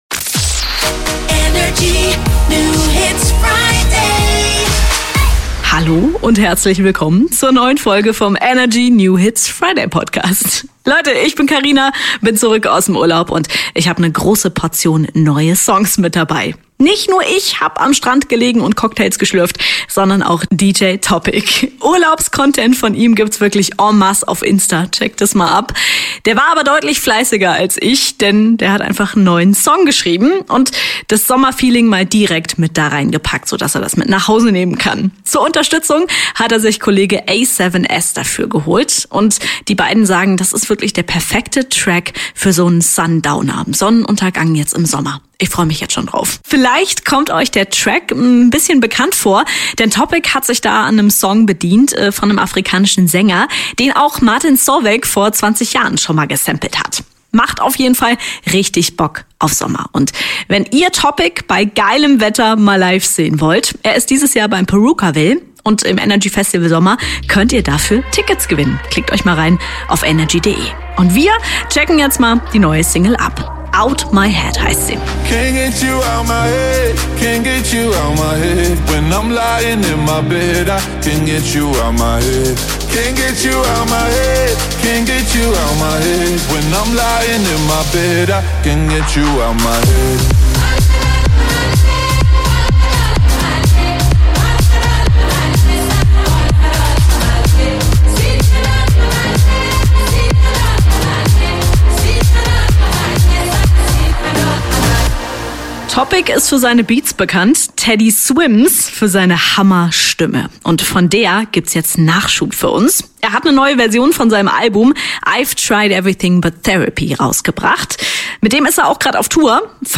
Musik